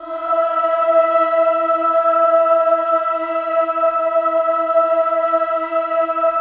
VOWEL MV11-R.wav